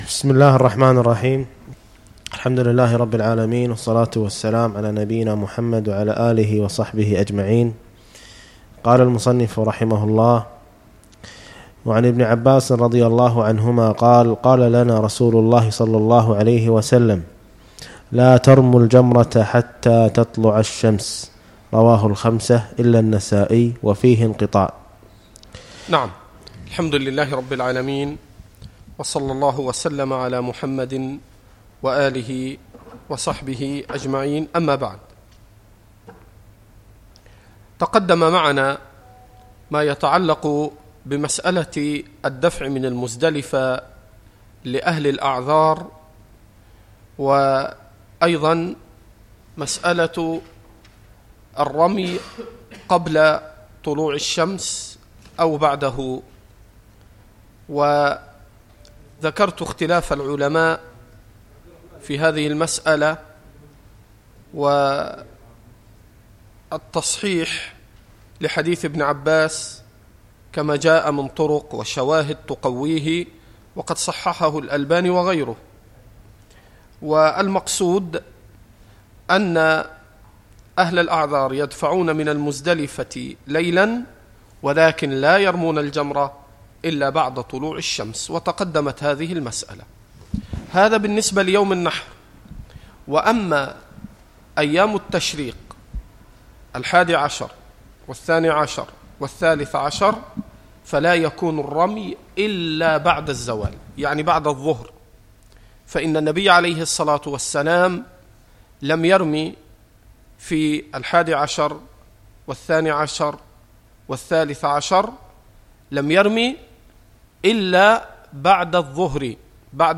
الدروس والمحاضرات